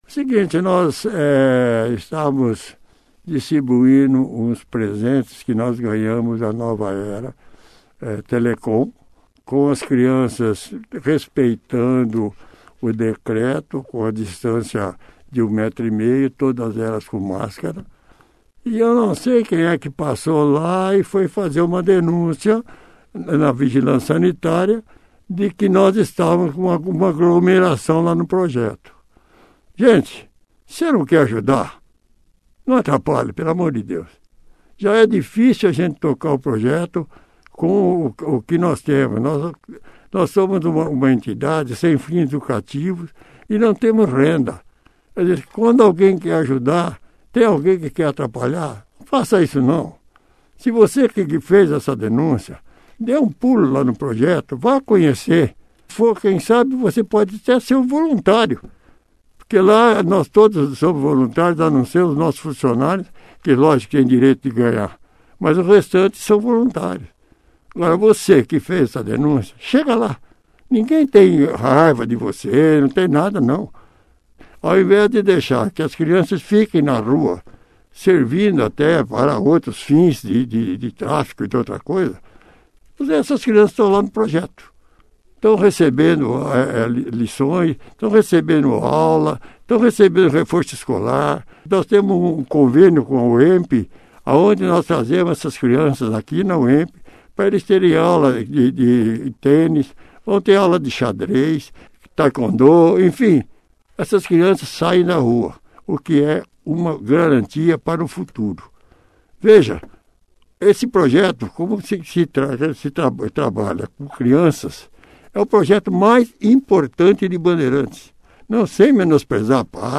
participou da 1ª edição do jornal Operação Cidade